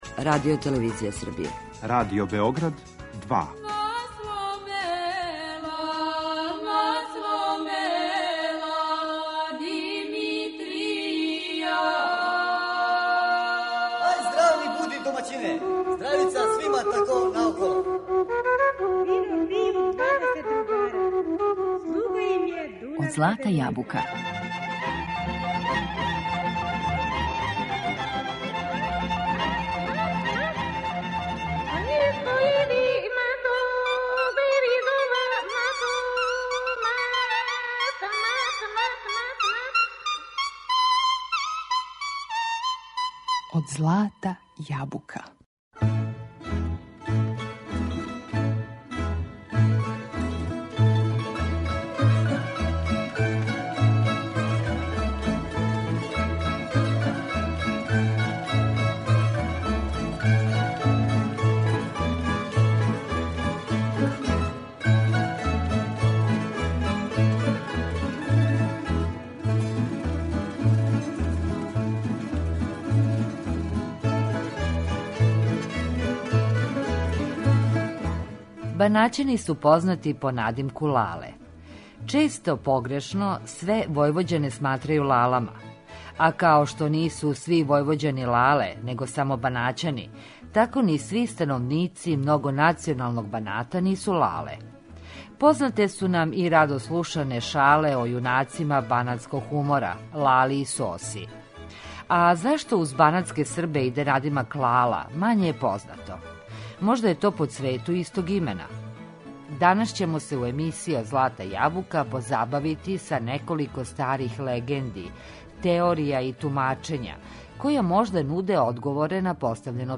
Данас ћемо се позабавити неколиким старим легендама, теоријама и тумачењима који можда нуде одговоре на постављено питање. А позабавићемо се и банатском музиком, бећарцима, песмама и народним играма.